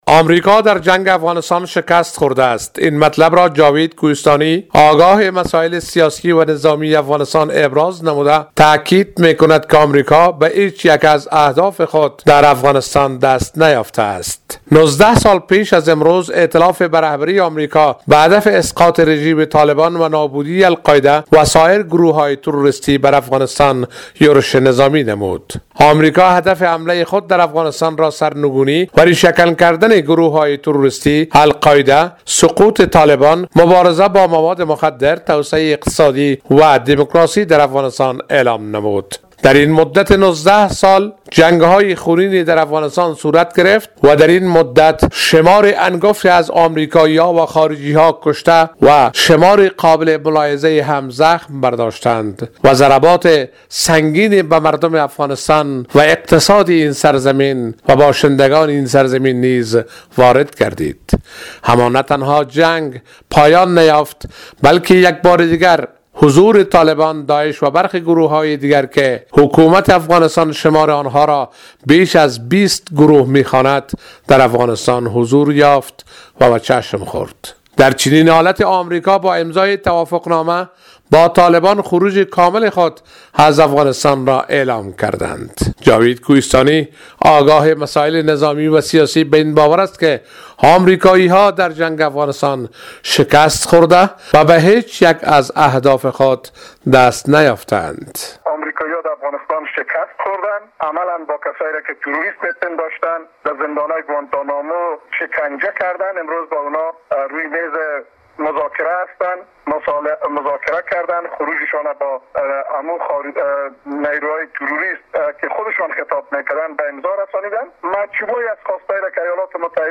از کابل